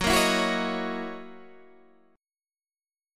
Gb13 chord